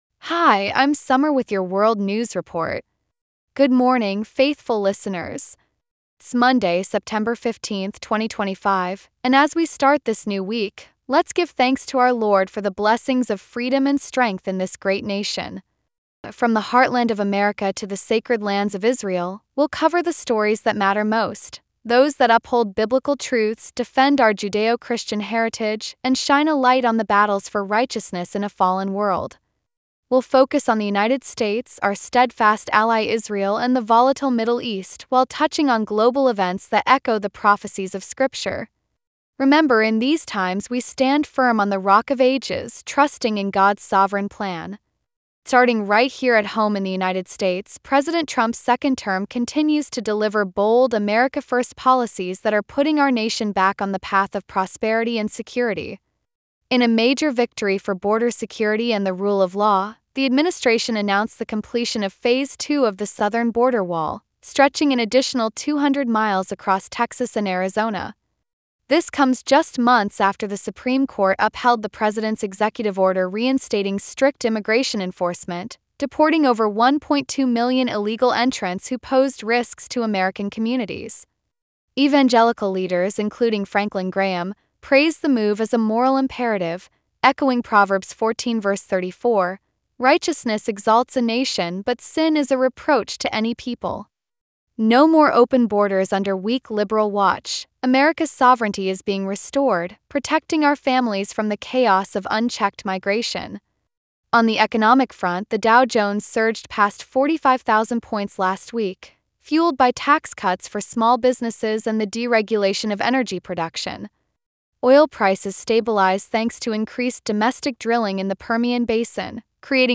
World News Report for Monday, September 15, 2025